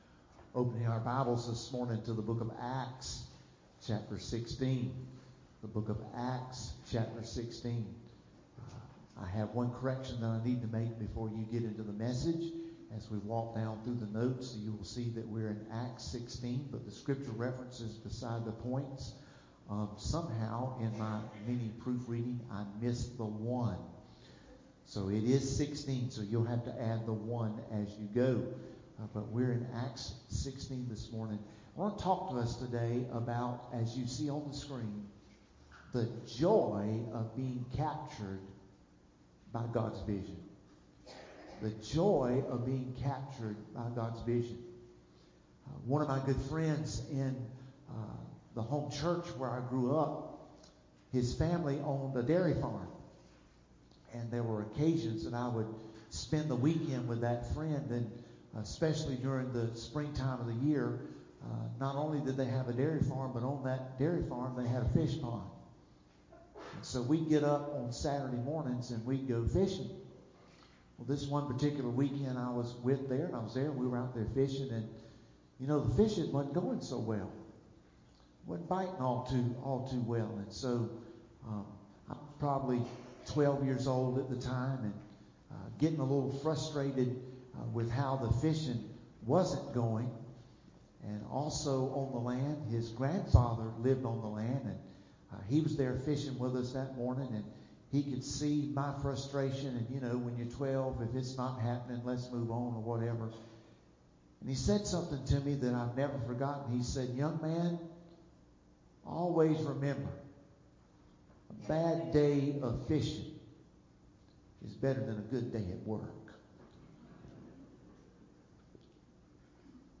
3-1-20-Website-Sermon-CD.mp3